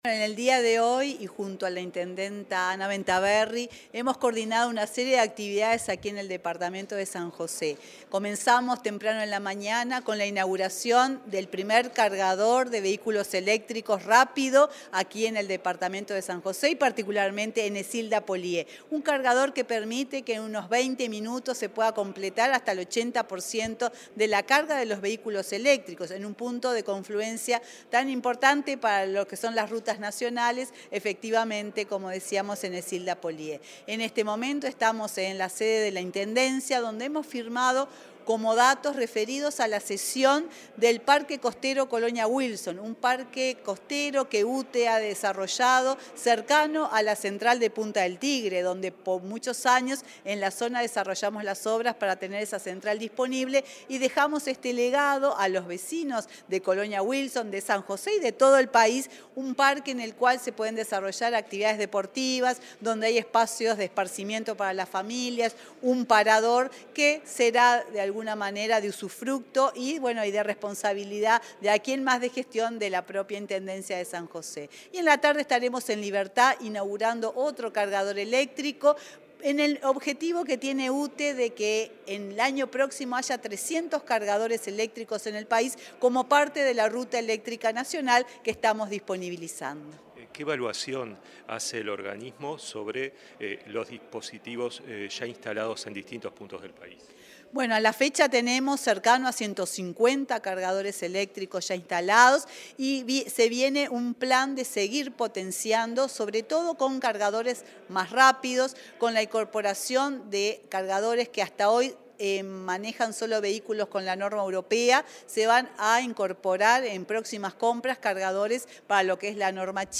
Entrevista a la presidenta de UTE, Silvia Emaldi
Entrevista a la presidenta de UTE, Silvia Emaldi 12/08/2022 Compartir Facebook X Copiar enlace WhatsApp LinkedIn Tras participar en la firma de acuerdos con la Intendencia de San José, este 12 de agosto, la presidenta de la empresa estatal realizó declaraciones a la Comunicación Presidencial.